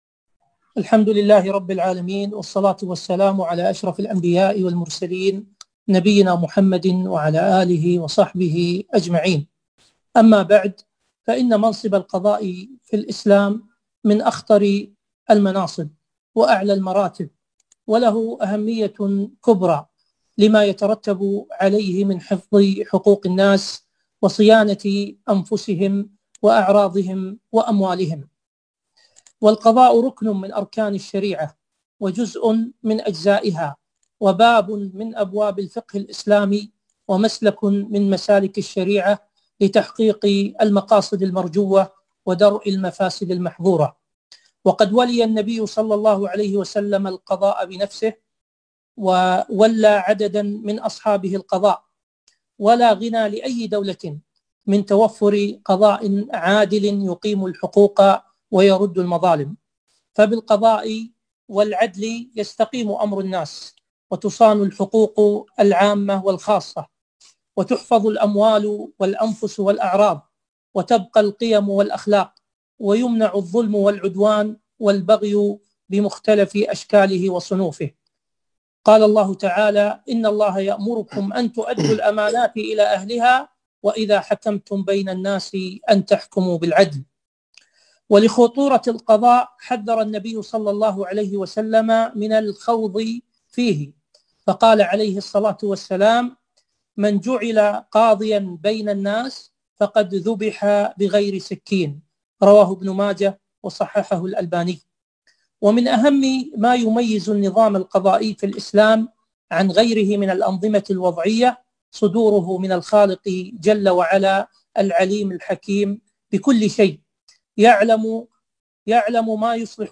كلمة - تولي المرأة القضاء في ميزان الشرع